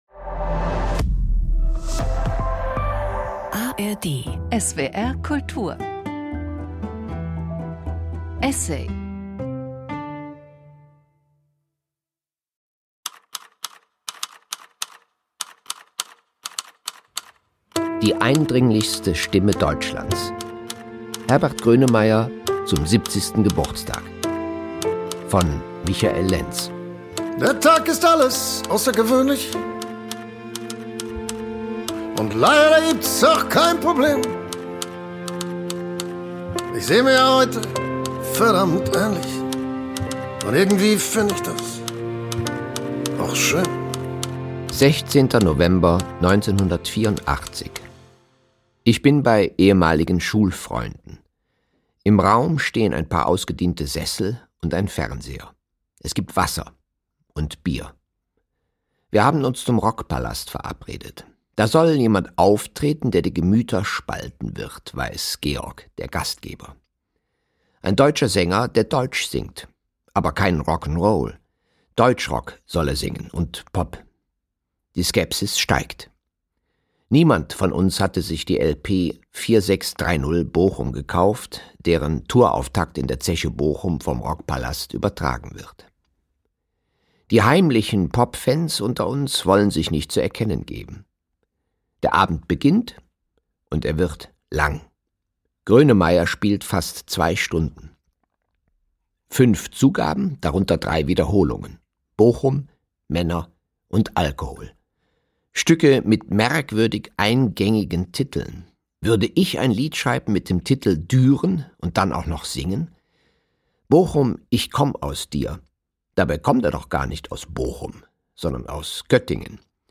Zum 70. Geburtstag gratuliert Michael Lentz Herbert Grönemeyer nun akustisch und geht in dem folgenden Radioessay der Frage nach, was Grönemeyer so unverwechselbar macht.